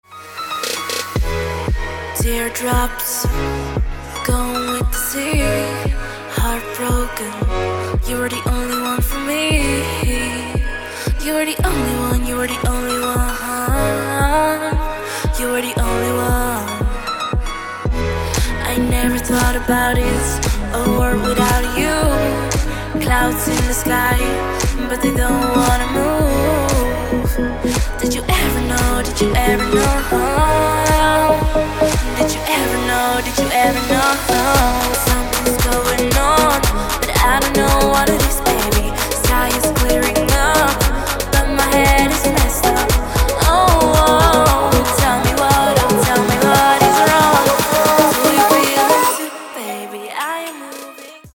• Качество: 160, Stereo
женский вокал
dance
club
house
vocal